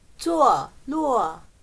坐落/Zuòluò/situado